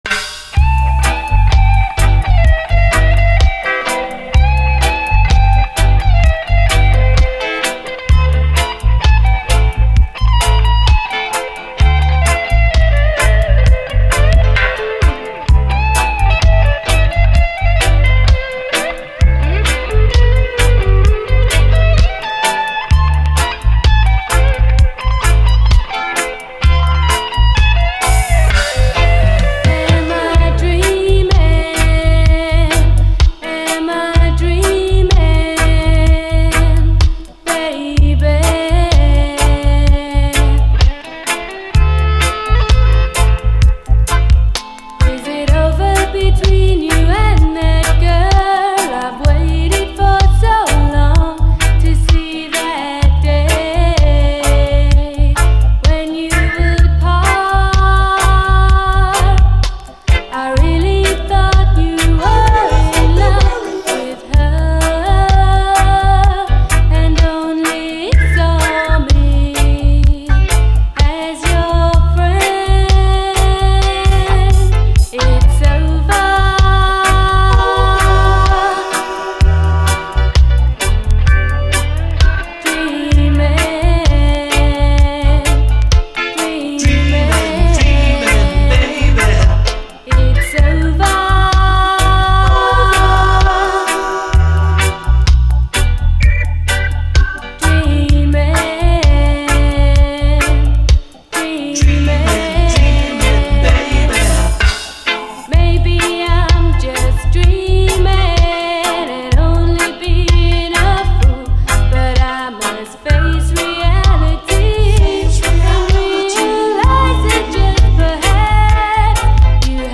REGGAE
悶絶のキラー・ドリーミーUKラヴァーズ！哀愁ほとばしるギターが最高にカッコいい。